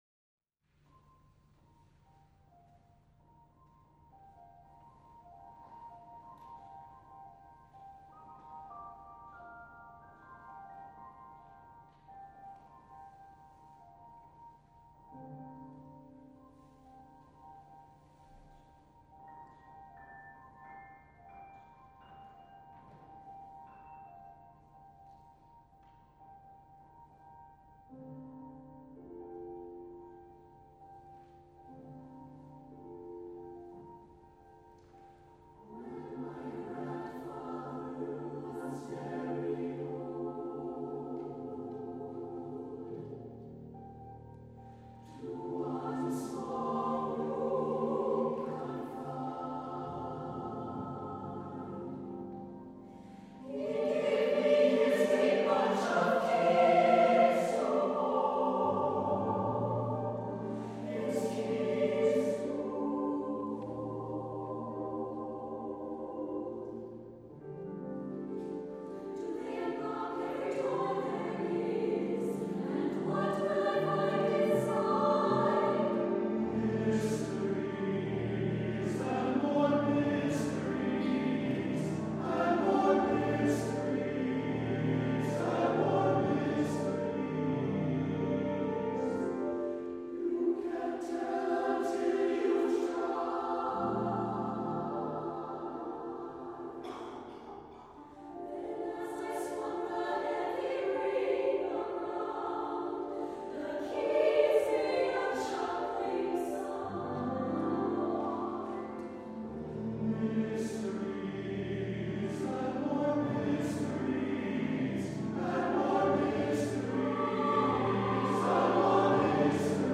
for SATB Chorus and Piano (1998)